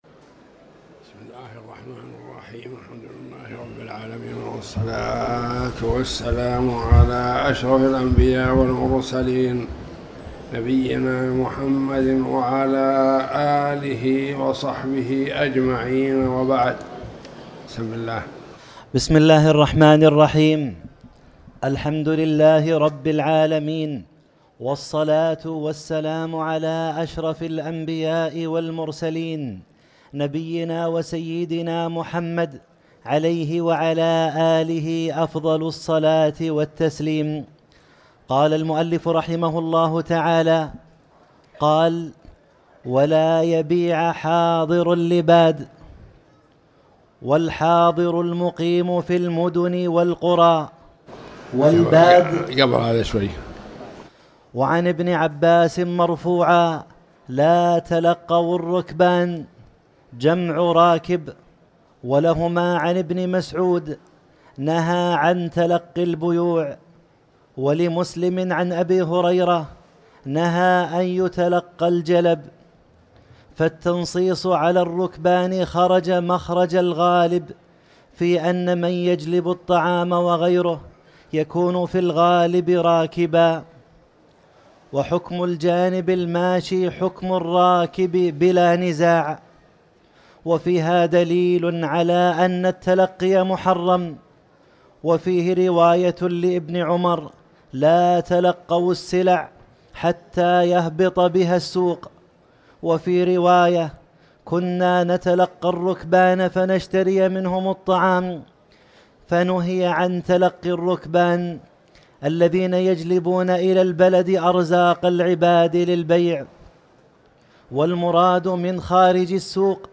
تاريخ النشر ٩ ربيع الثاني ١٤٤٠ هـ المكان: المسجد الحرام الشيخ